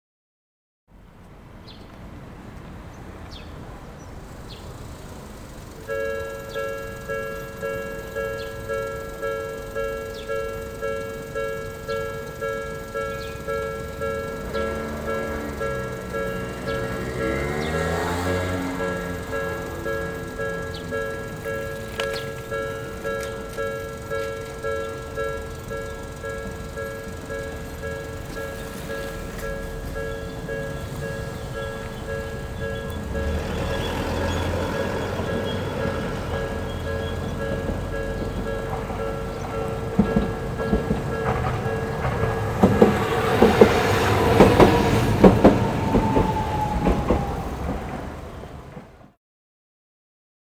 機械のモーター音やアナウンス、発車の合図......。地下鉄、都電荒川線、都営バスのそれぞれの場所でしか聞くことができない音を収録しました。
第7回都電荒川線「遮断機音」
第7回 都電荒川線「遮断機音」 荒川車庫前停留場から荒川遊園地前停留場までの間にある遮断機の音。電車だけでなく、車や自転車など行き交う人々の音が聞こえます。